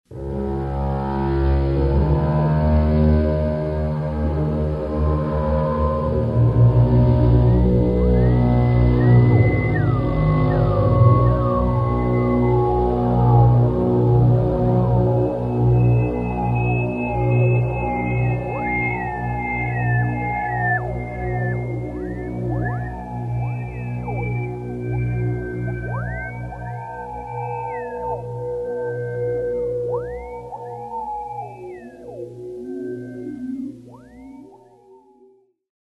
Экспериментальные звучания контрабаса в психоделическом стиле